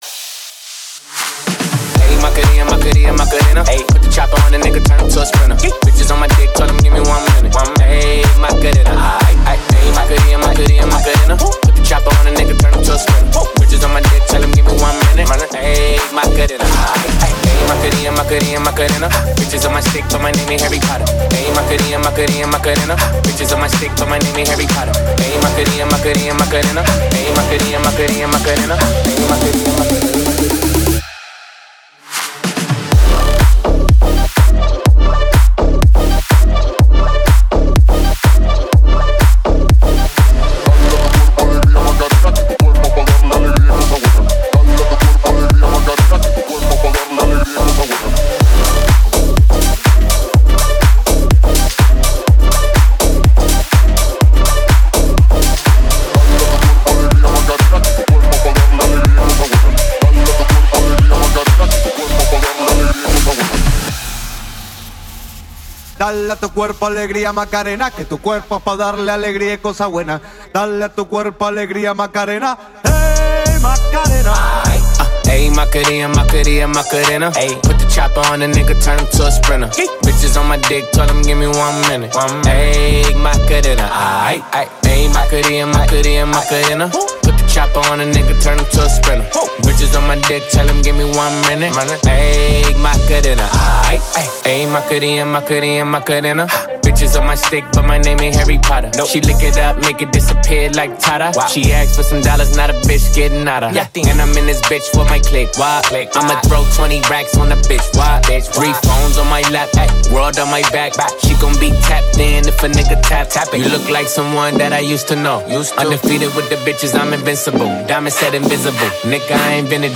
зажигательный трек